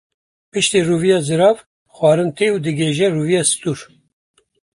Pronúnciase como (IPA)
/zɪˈɾɑːv/